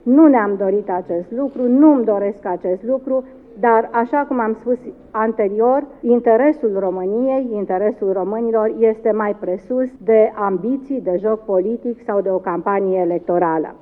Prim ministrul explica, astăzi, că demersul a fost generat de faptul că şeful statului nu a anunţat până acum ce hotărâre a luat în cazul celor două propuneri. În condiţiile preluării preşedinţiei rotative a Consiliului Uniunii Europene, România nu-şi poate permite schimbări de miniştri, sublinia premierul Viorica Dăncilă: